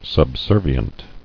[sub·ser·vi·ent]